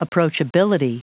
Prononciation du mot approachability en anglais (fichier audio)
Prononciation du mot : approachability